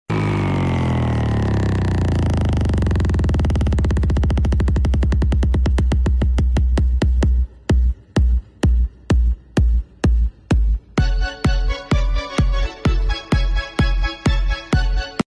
Ducati multistrada 1200 Desmo service sound effects free download